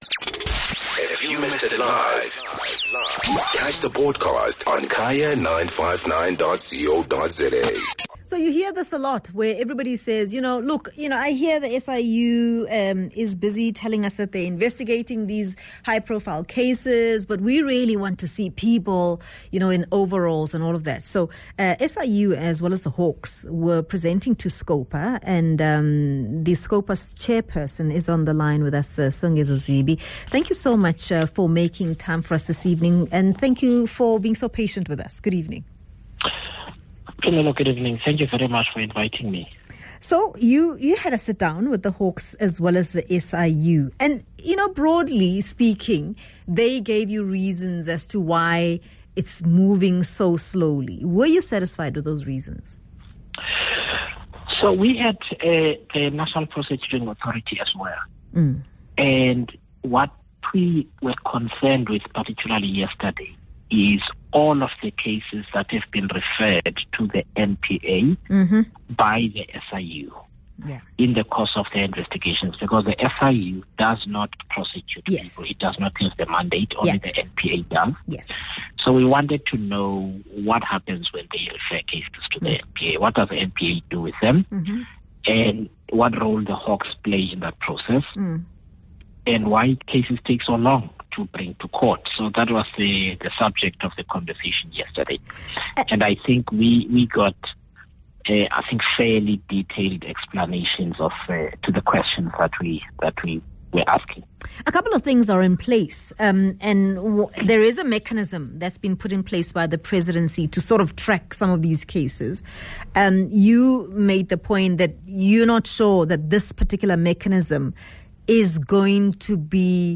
speaks to Scopa chairperson, Songezo Zibi.